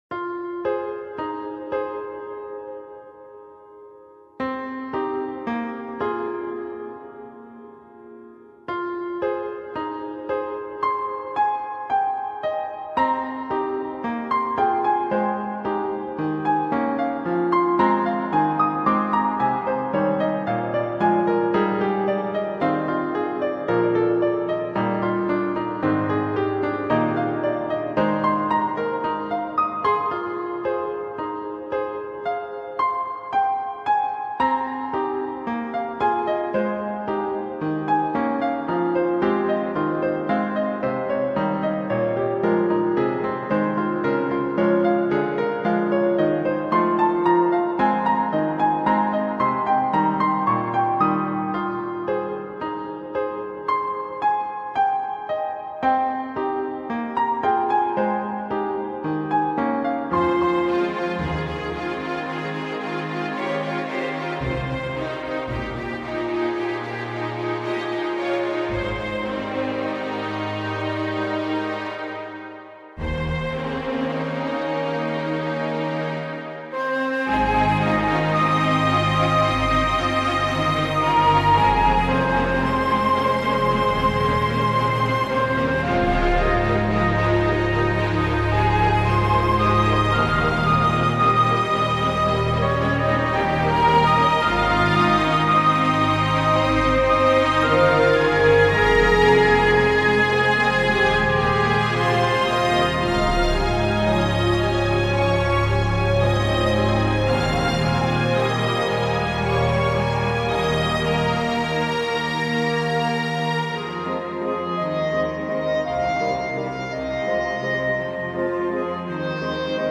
The mood of the music is either action or emotion.
The piano begins by looking around.
Then the power of the triplets and french horns awakens you.